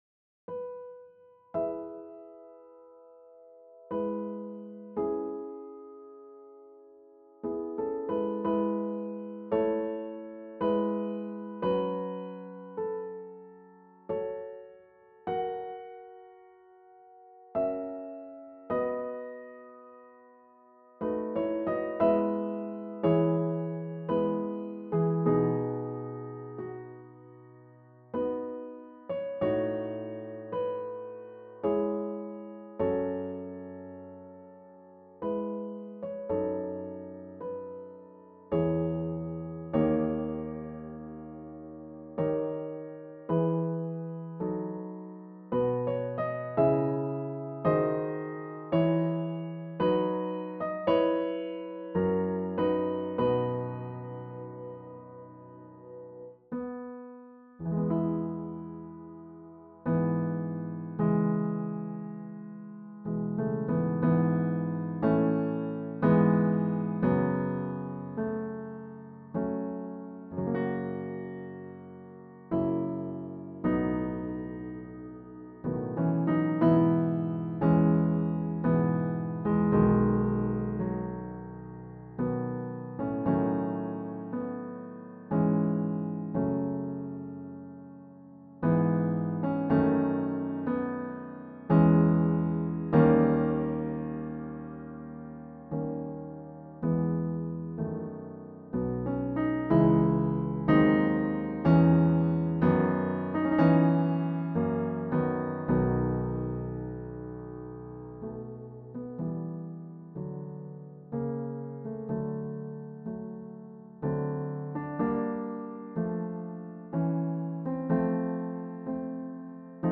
The pieces in this collection are my versions of some well-known piano compositions or extracts thereof.1  What struck me about each of them is how much the composer was able to say in just a few notes, a mere fistful of them.
As odd as this may sound, I didn't actually play any of these pieces on a piano. The performances were put together on a computer, where my job was to decide for each note just when it should hit, how hard, and for how long.2